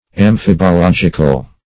Search Result for " amphibological" : The Collaborative International Dictionary of English v.0.48: Amphibological \Am*phib`o*log"ic*al\ ([a^]m*f[i^]b`[-o]*l[o^]j"[i^]*kal), a. Of doubtful meaning; ambiguous.